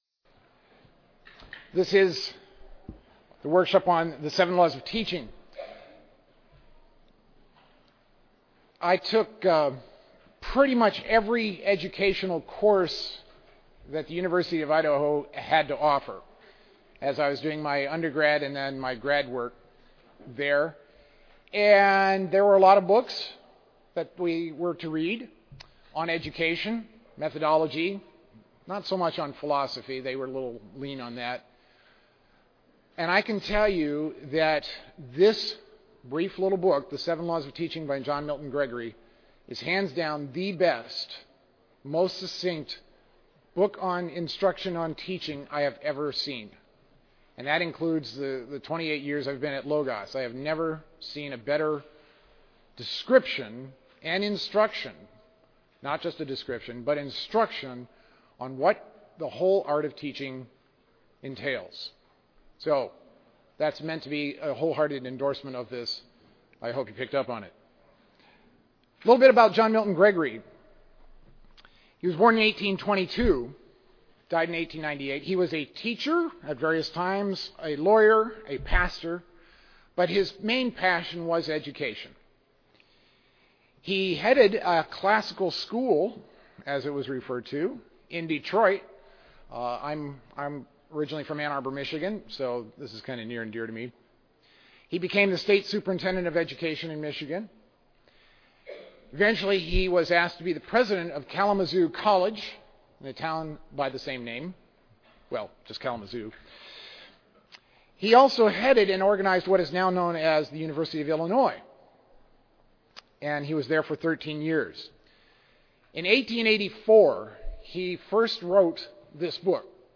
Mar 11, 2019 | All Grade Levels, Conference Talks, General Classroom, Library, Media_Audio, Workshop Talk | 0 comments
Additional Materials The Association of Classical & Christian Schools presents Repairing the Ruins, the ACCS annual conference, copyright ACCS.